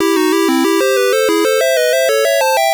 One of the jingles that plays at the start of a level
Source Recorded from the Sharp X1 version.